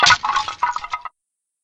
lose_a_life.ogg